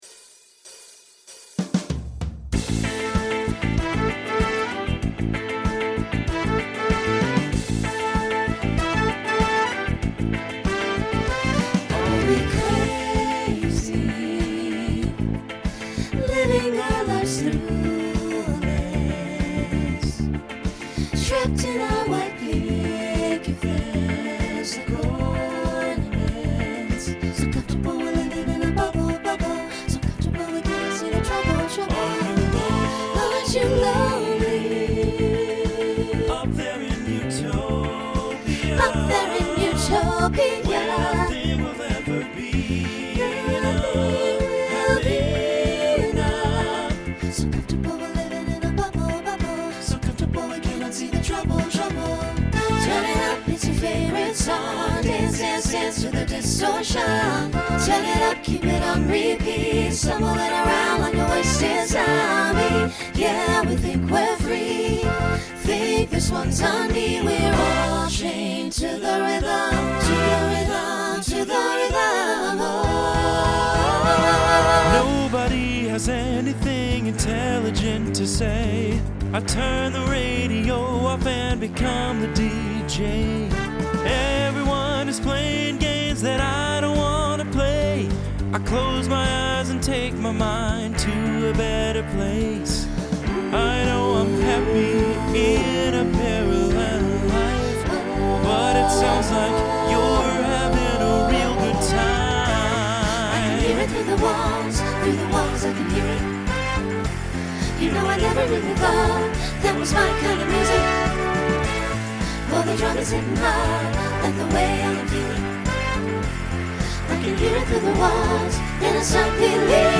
Genre Pop/Dance
Instrumental combo
Voicing SATB